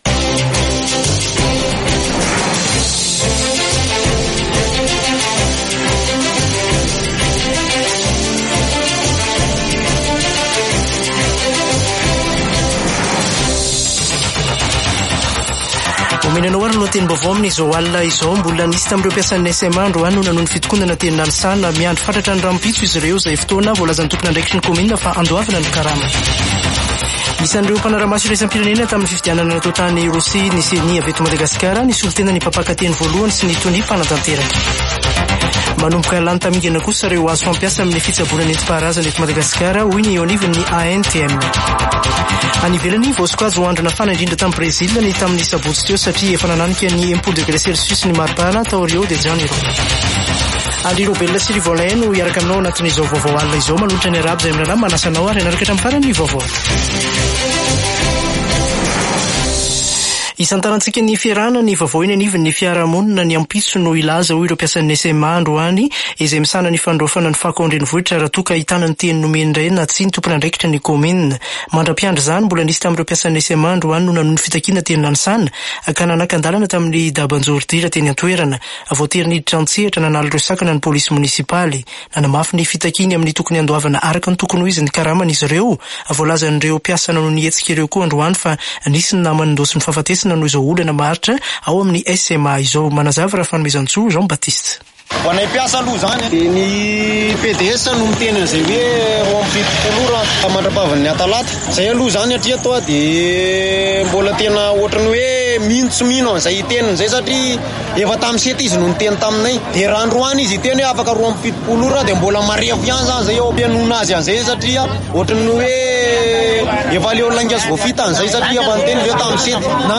[Vaovao hariva] Alatsinainy 18 marsa 2024